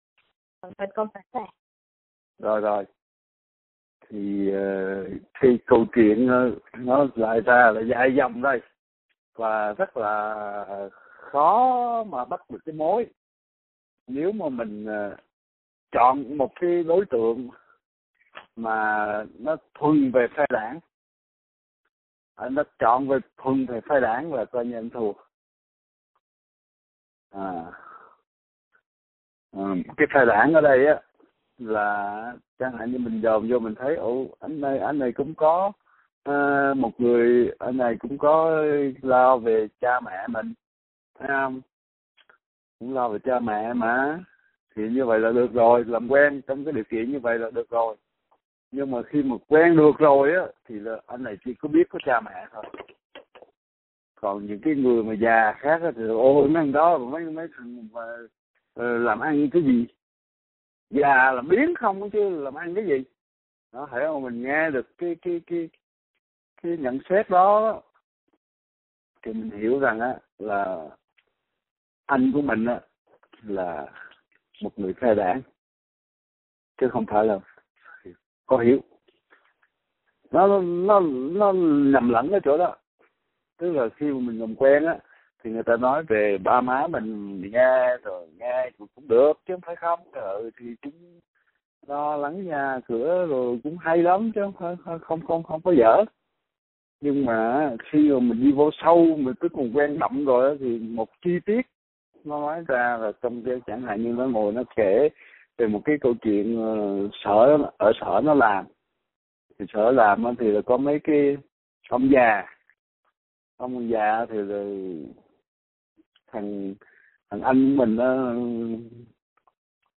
Mời các bạn lắng nghe bài thâu âm Thầy trình bày về Mẹ Chồng Nàng Dâu trên khía cạnh đời và đạo